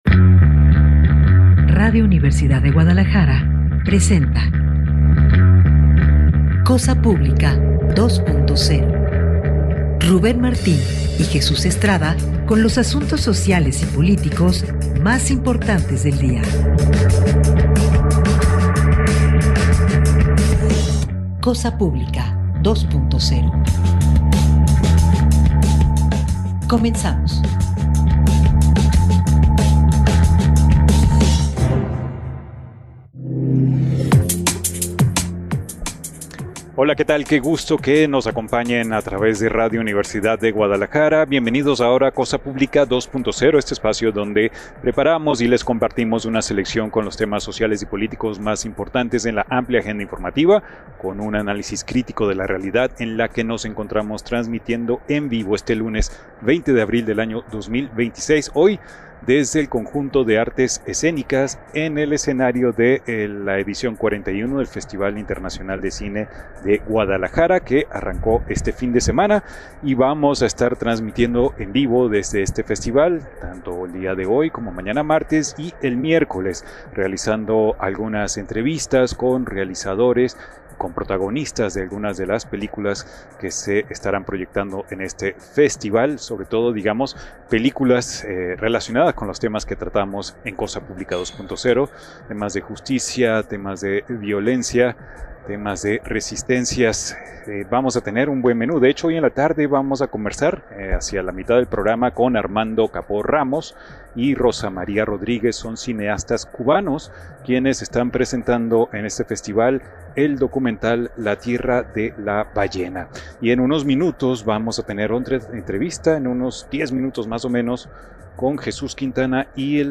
desde el Festival Internacional de Cine en Guadalajara.